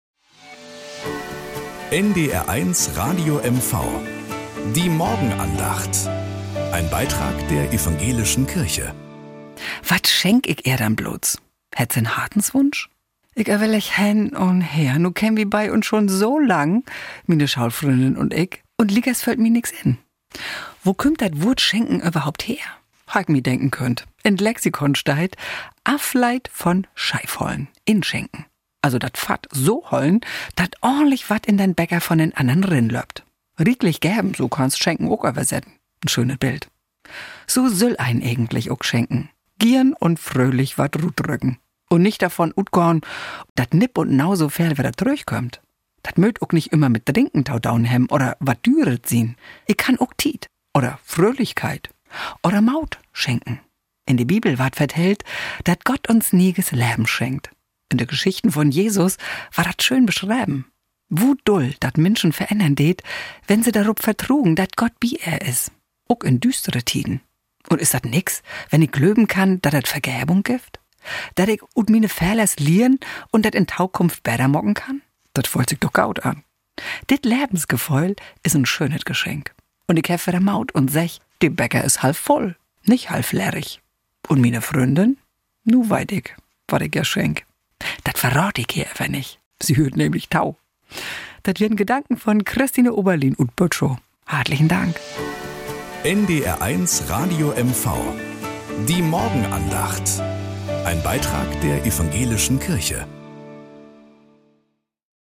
Morgenandacht auf NDR 1 Radio MV
Um 6:20 Uhr gibt es in der Sendung "Der Frühstücksclub" eine Morgenandacht. Evangelische und katholische Kirche wechseln sich dabei ab.